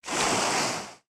foundry-pour-2.ogg